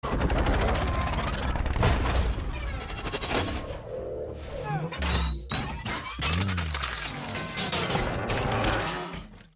На этой странице собраны звуки из вселенной Трансформеров: эффекты трансформации, футуристические боевые режимы, голоса известных автоботов и десептиконов.
Звук превращения трансформера из самолета в робота